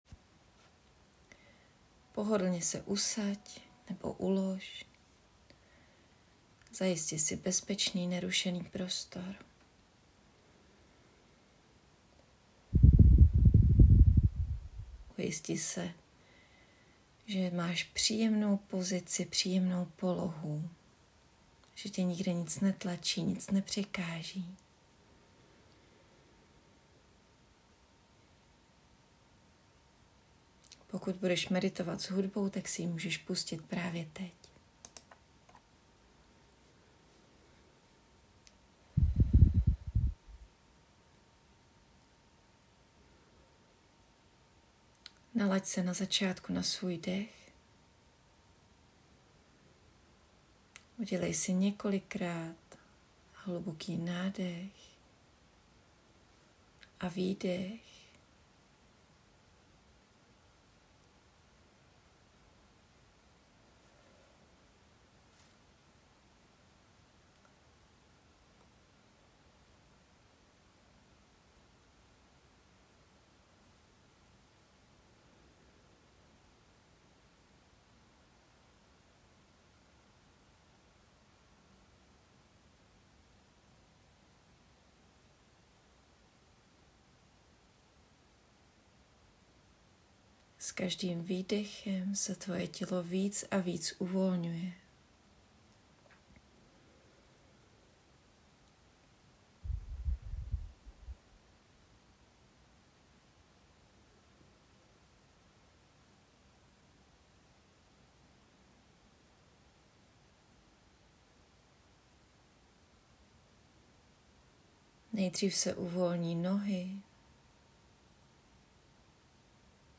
Meditace-voda.mp3